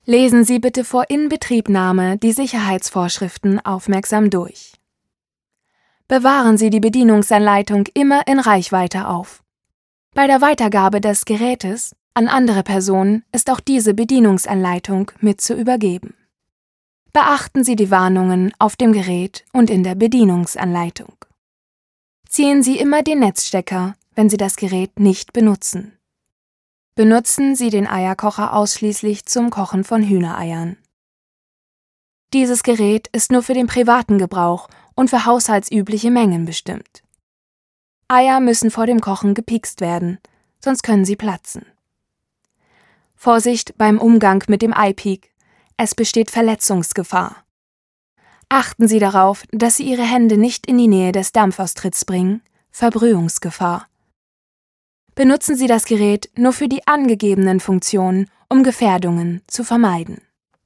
Sprecherin deutsch
norddeutsch
Sprechprobe: Industrie (Muttersprache):
german female voice over artist